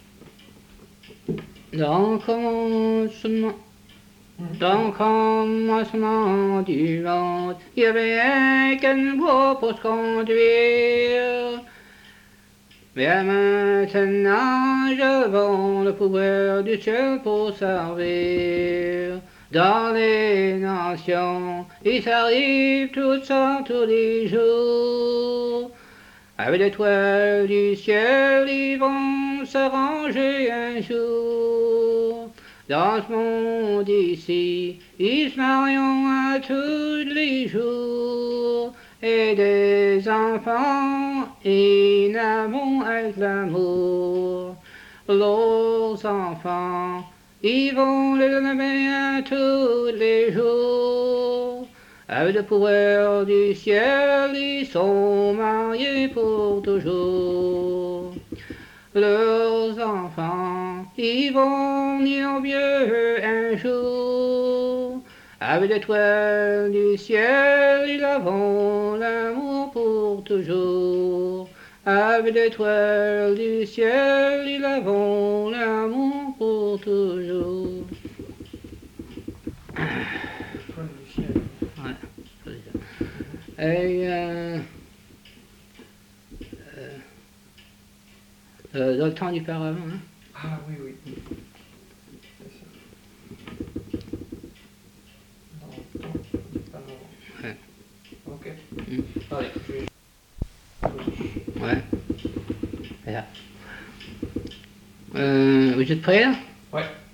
Chanson Item Type Metadata
Emplacement La Grand'Terre